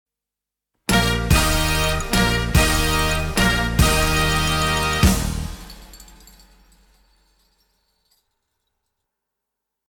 Tusch 3x_1.mp3